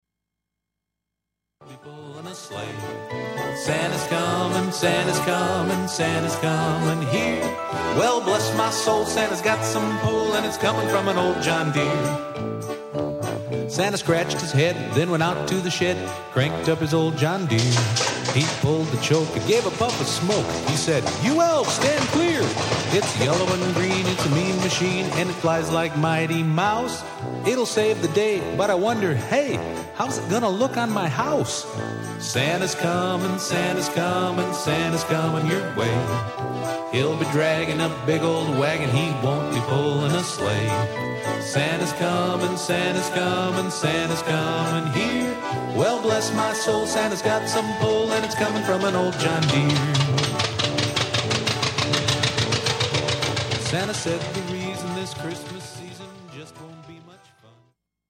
Real Farmers making Real Music using Real Tractors